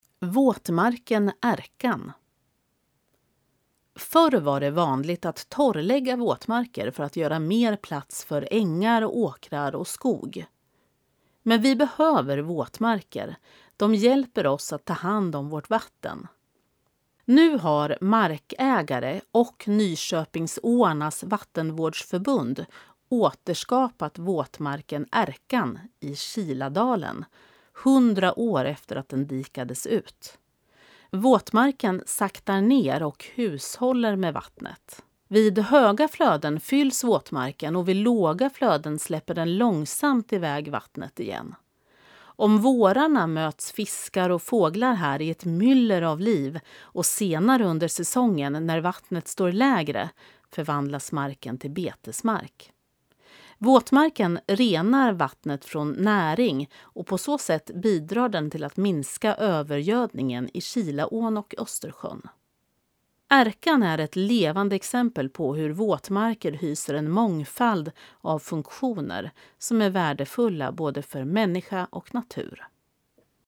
Klicka här för att lyssna till texten, inläst av en professionell uppläsare